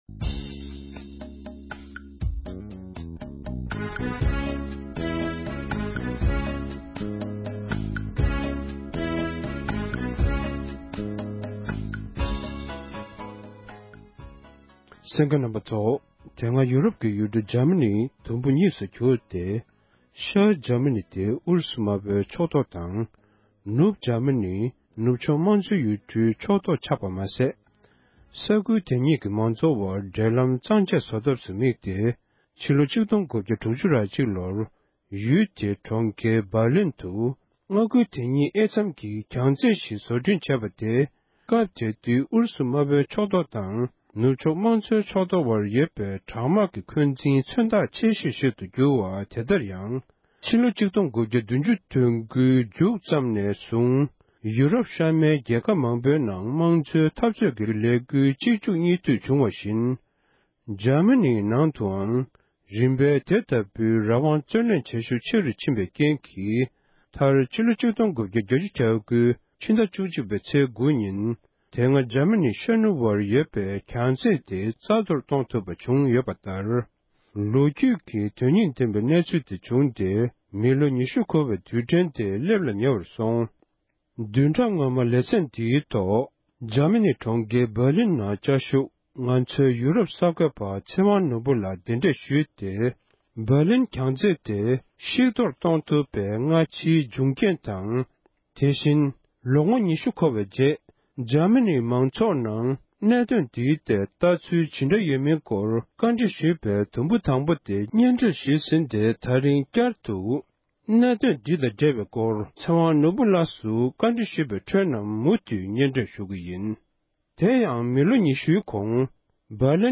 གནས་འདྲི་ཞུས་པའི་དུམ་མཚམས་གཉིས་པར་གསན་རོགས༎